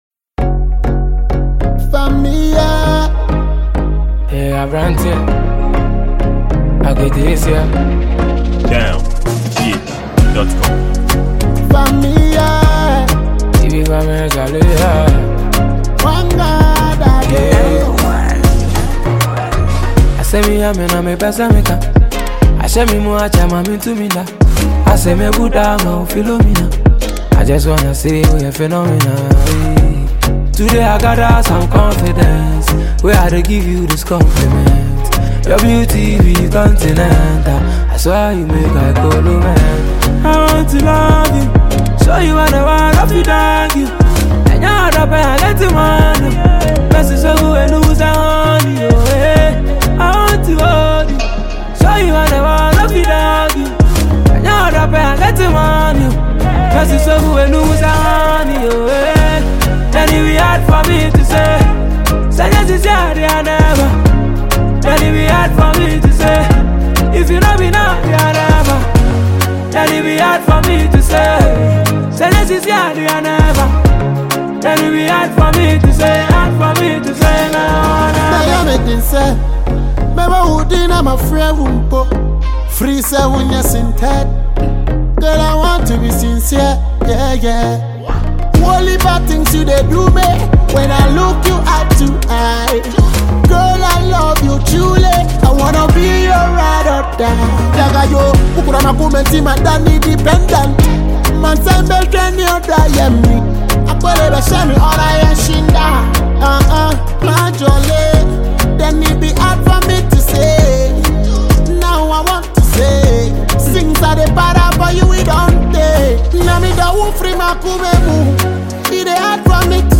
afrobeat reggae-dancehall